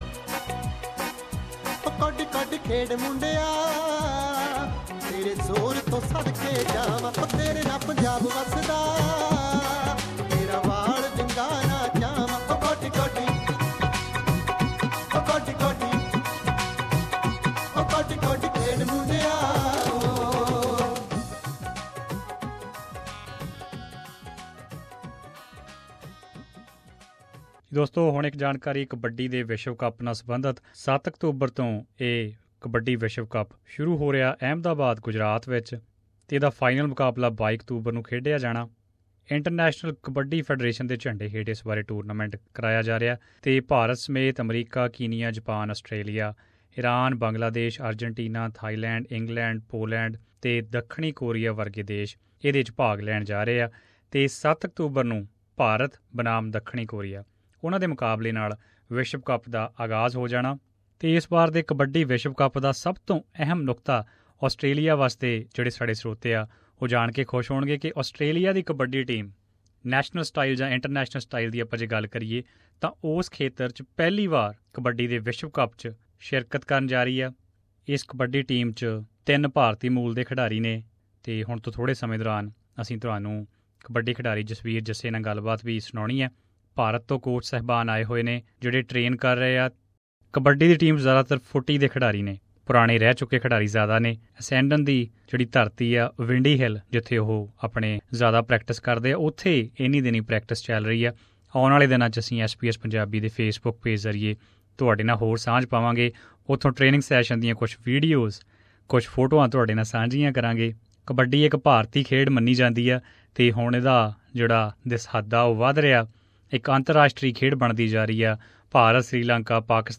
SBS Punjabi View Podcast Series